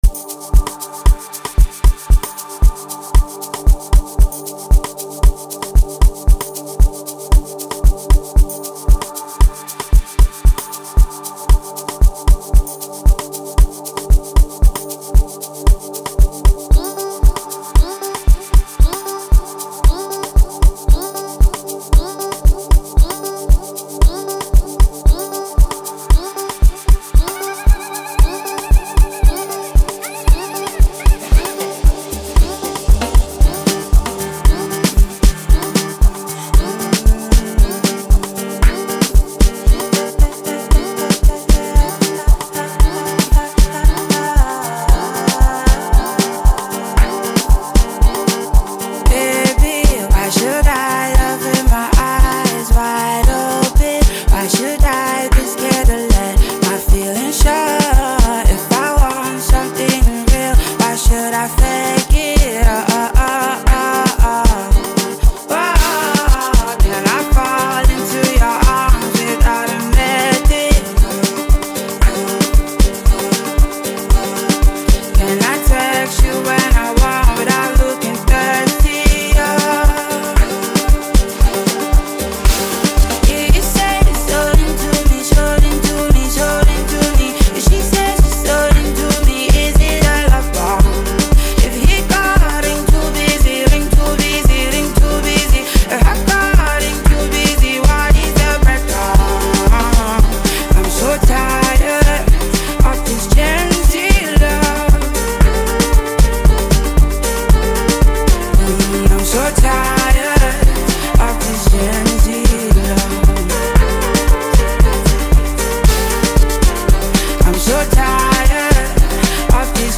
vocal finesse